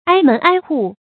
挨门挨户 āi mén āi hù
挨门挨户发音